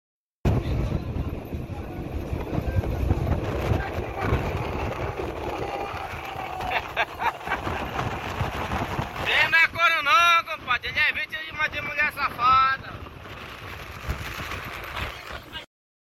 Aproximando aquela chuva vindo do sound effects free download